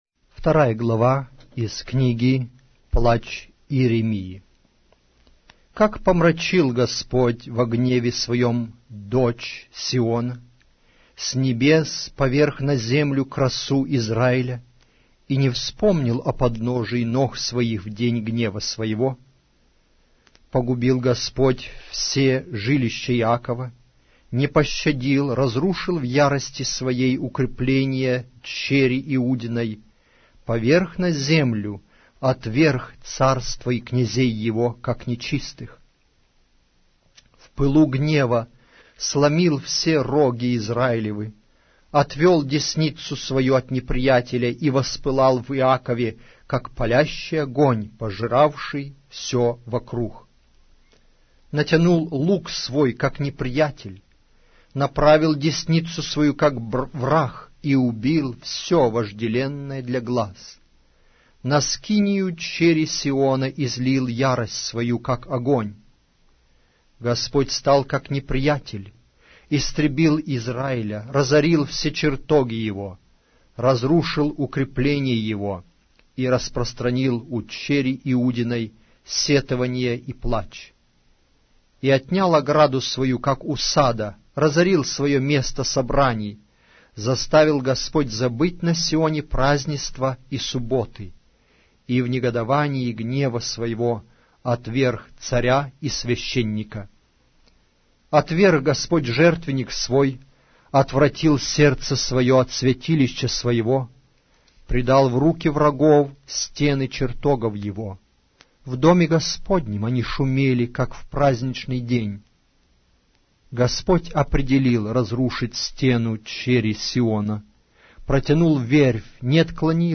Аудиокнига: Плач Иеремии